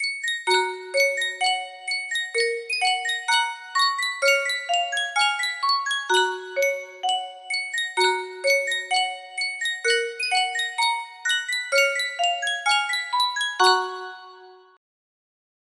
Sankyo Music Box - The Caissons Go Rolling Along WQ music box melody
Full range 60